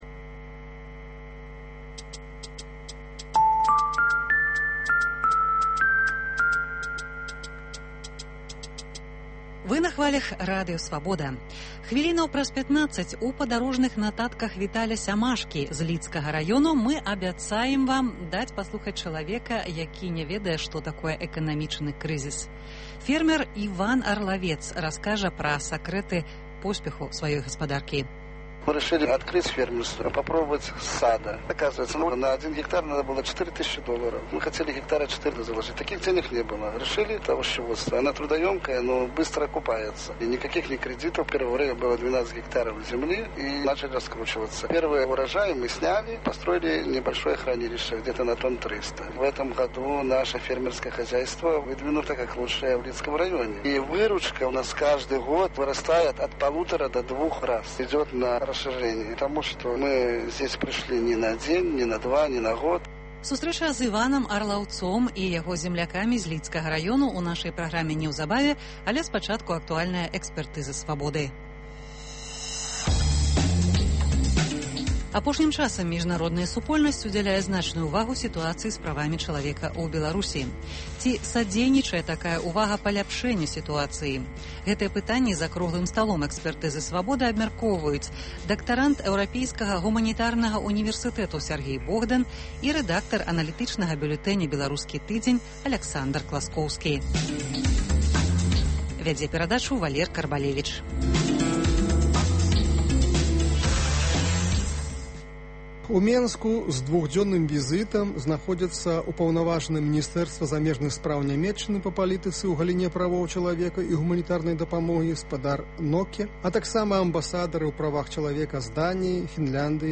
Ці апраўданыя папрокі на адрас Эўразьвязу за ігнараваньне гэтай праблемы? Ці садзейнічае міжнародная ўвага паляпшэньню сытуацыі з правамі чалавека ў Беларусі? Гэтыя пытаньні абмяркоўваюць за круглым сталом